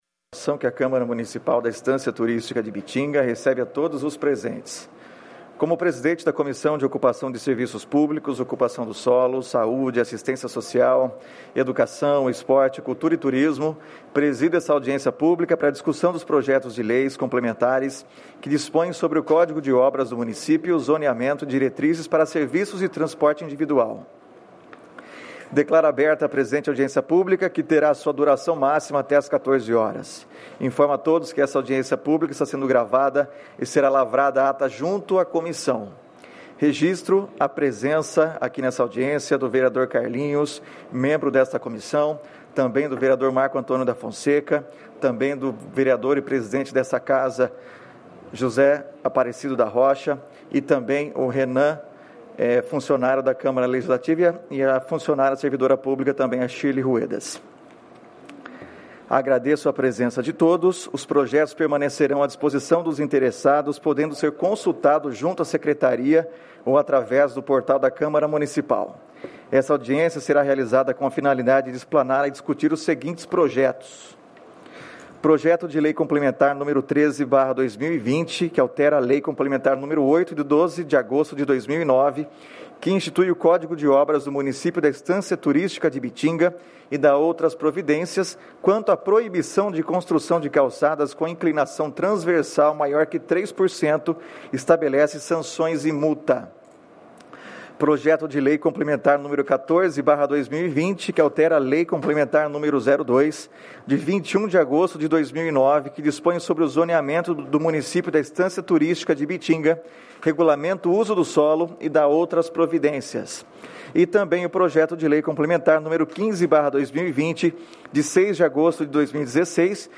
Audiência Pública de 16/12/2020